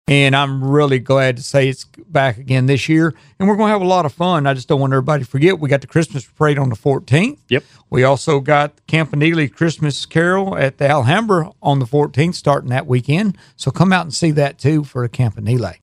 Hopkinsville Mayor J. R. Knight says he is excited about upcoming events in the city to help celebrate the Christmas Holiday season.